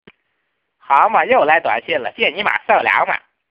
分类: 短信铃声